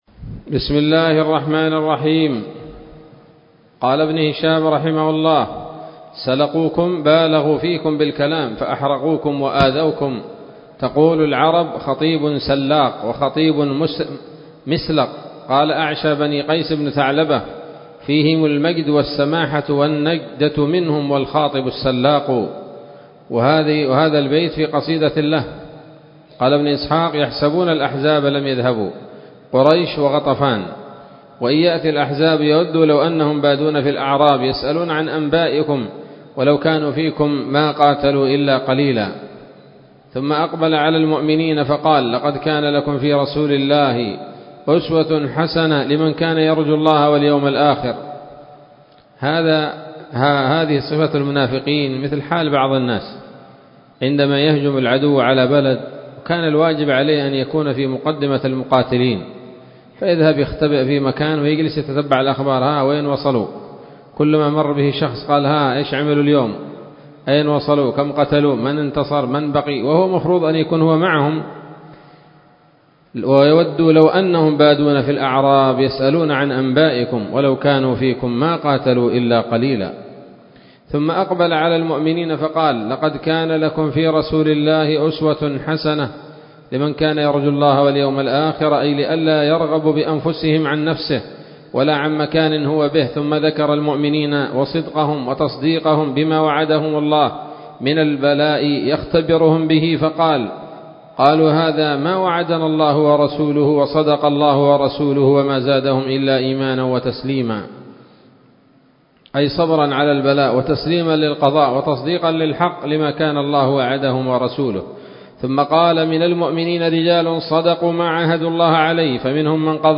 الدرس الحادي عشر بعد المائتين من التعليق على كتاب السيرة النبوية لابن هشام